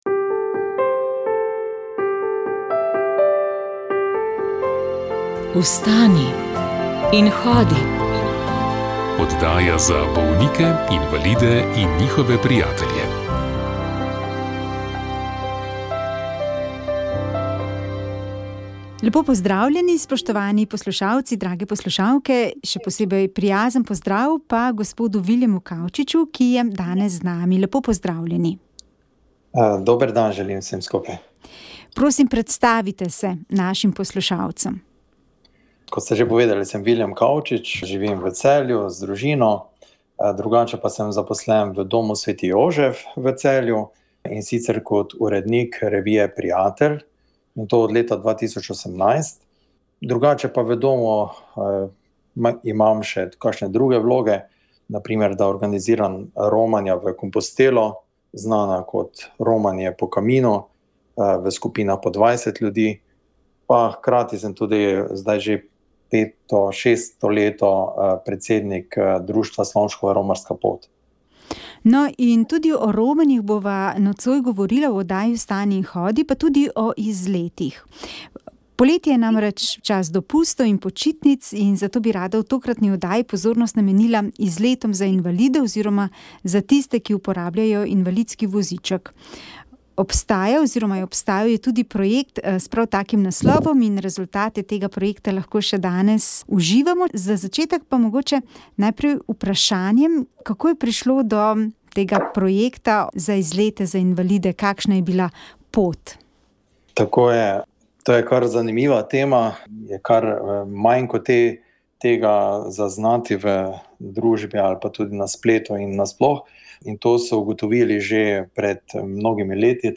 Cerkev na avstrijskem Koroškem je od nekdaj bila gonilna sila tudi za slovensko kulturno udejstvovanje, ki je dvigovalo in narodnostno oblikovalo narod. V današnjem svetu pa sta tako vera in slovenska beseda pred novimi izzivi. Tako razmišlja škof krške škofije v Celovcu dr. Jože Marketz. Svoje razmišljanje je pripravil za letošnje Koroške kulturne dneve v Ljubljani, za večer, ki ga je organiziral Klub koroških Slovencev v Ljubljani.